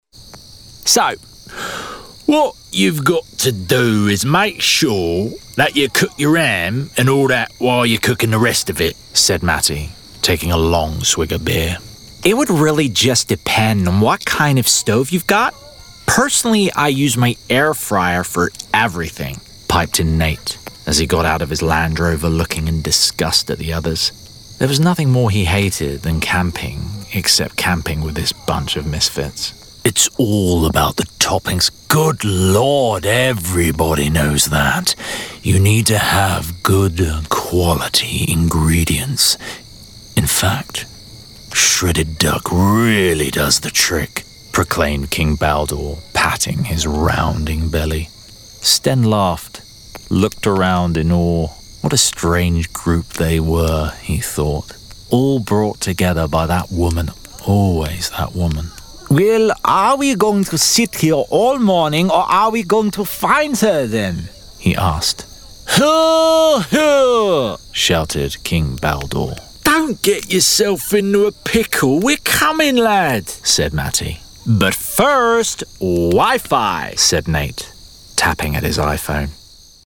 Voice Reel
Audiobook - Character reel